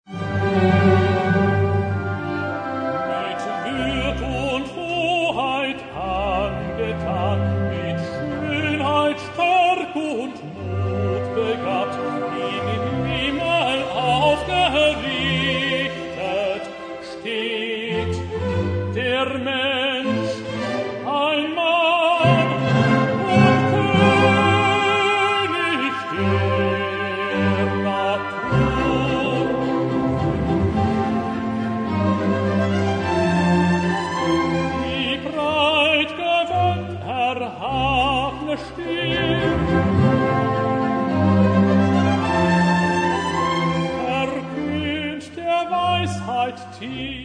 Arie Mit Würd’  und Hoheit